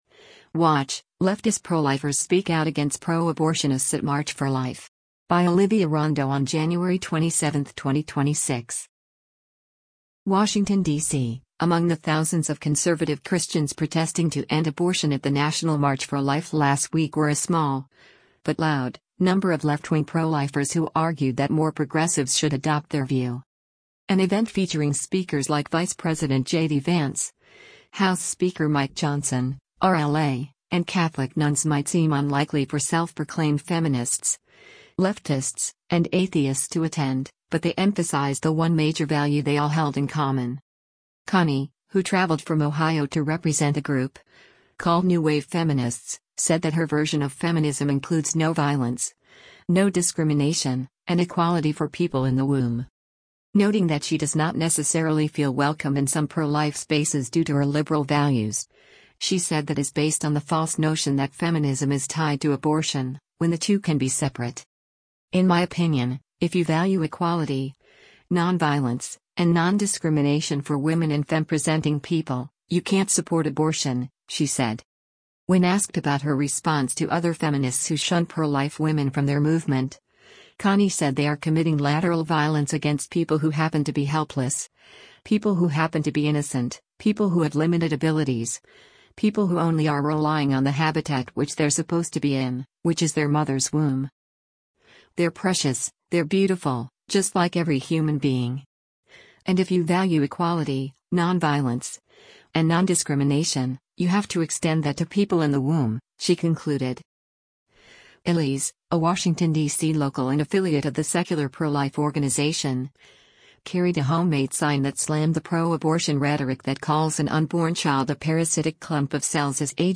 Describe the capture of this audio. Washington, D.C. — Among the thousands of conservative Christians protesting to end abortion at the national March for Life last week were a small, but loud, number of left-wing pro-lifers who argued that more progressives should adopt their view.